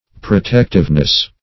protectiveness - definition of protectiveness - synonyms, pronunciation, spelling from Free Dictionary
Protectiveness \Pro*tect"ive*ness\, n.